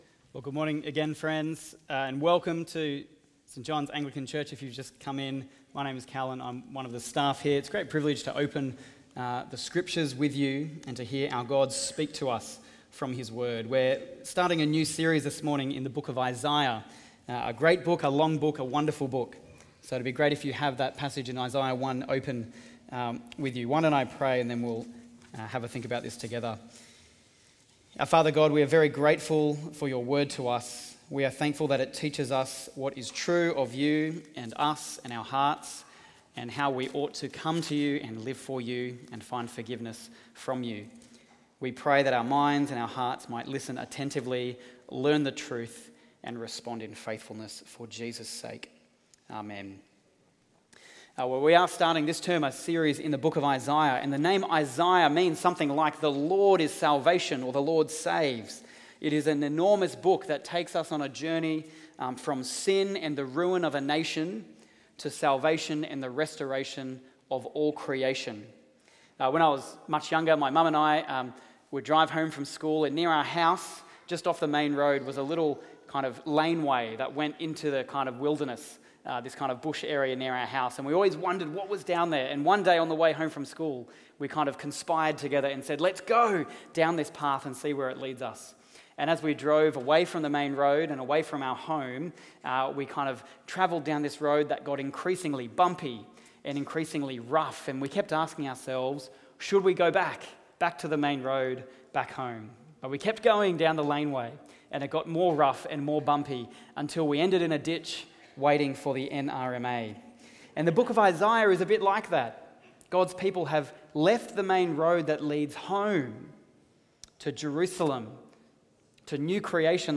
Watch the full service on YouTube or listen to the sermon audio only.